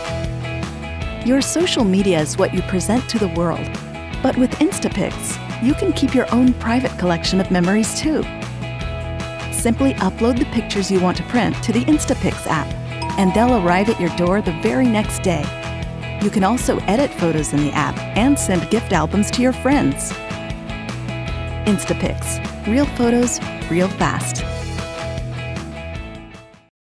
English (American)
Commercial, Natural, Reliable, Friendly, Warm
Explainer